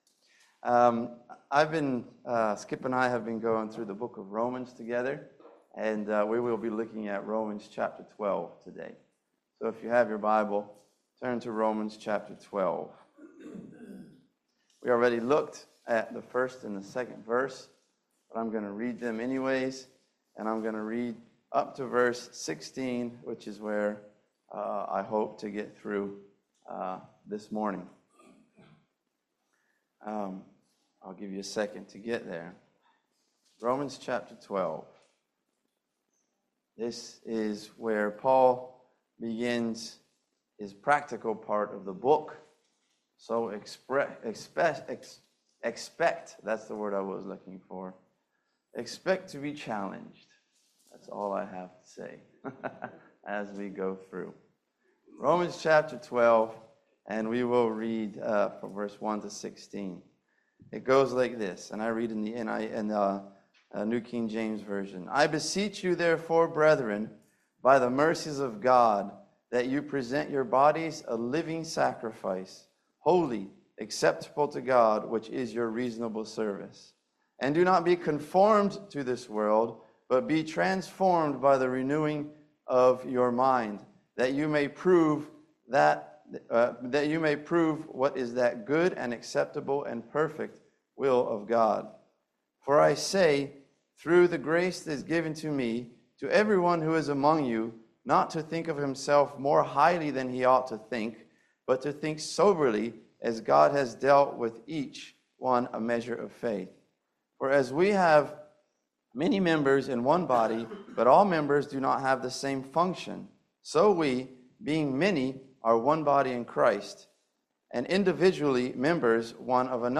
Romans 12:1-16 Service Type: Family Bible Hour Evaluate & cooperate with a good attitude.